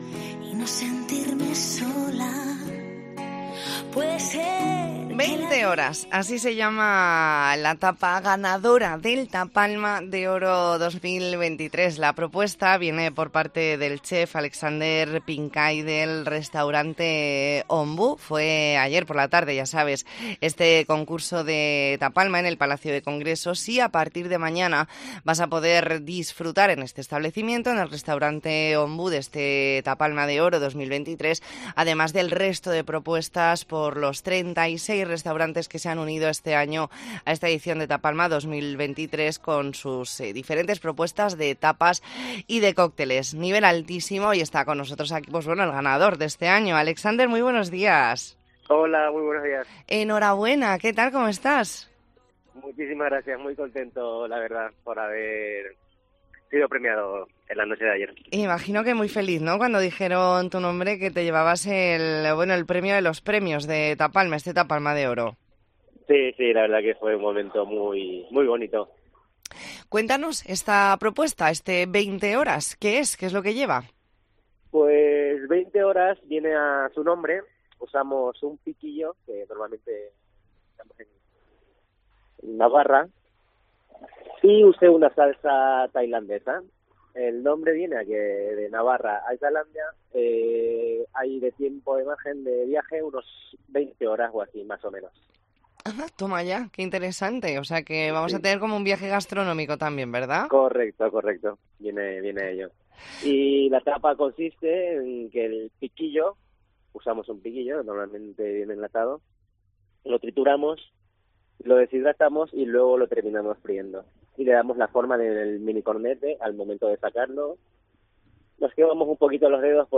Entrevista en La Mañana en COPE Más Mallorca, martes 21 de noviembre de 2023.